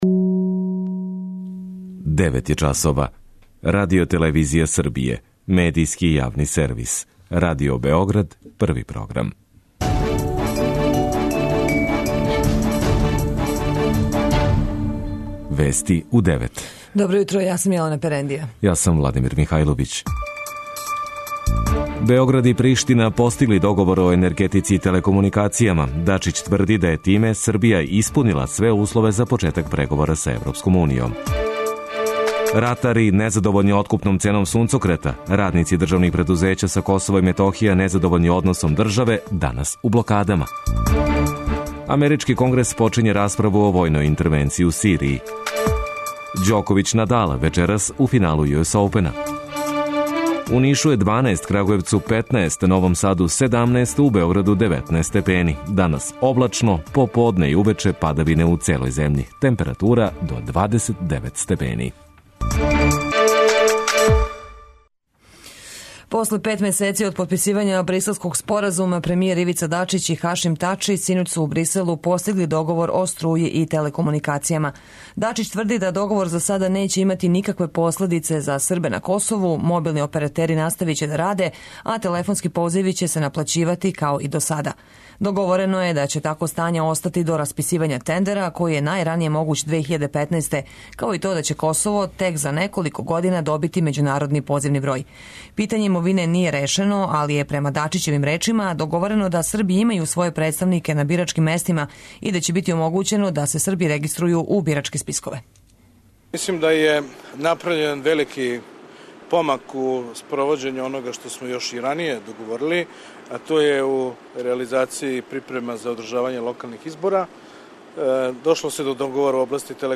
преузми : 10.23 MB Вести у 9 Autor: разни аутори Преглед најважнијиx информација из земље из света.